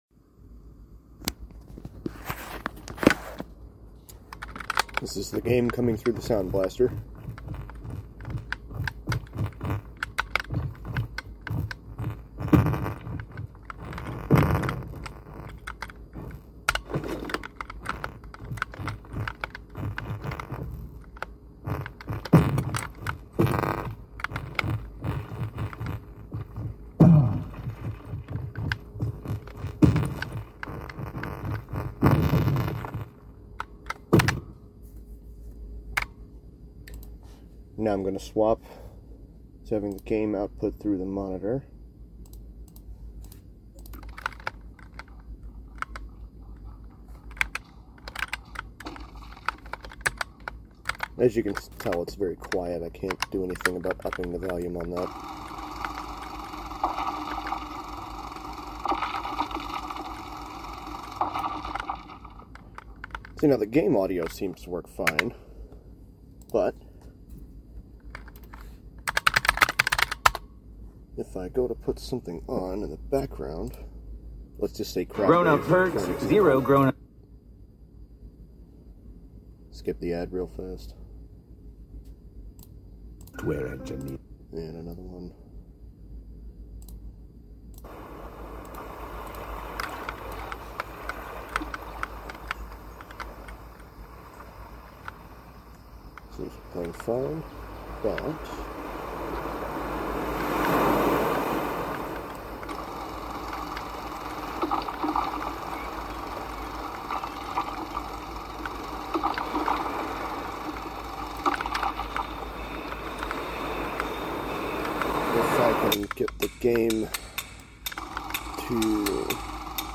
Sound Cracking/Popping using Soundblaster PCIe card
Hello SE2 devs, I've been having this issue ever since I installed the game - If I have the game audio come out through my Soundblaster card I get this crackling and popping sounds almost constantly.
I made a recording on my phone, however the website doesn't allow .m4a files - so I converted it to .mp3. Can confirm audio has not changed during conversion.
When the game sound comes through the soundblaster It's 100% of the time if I'm playing something like music or a video in the background, and you'll hear for yourself what it sounds like with no audio besides the game.
SE2 Sound Bug.mp3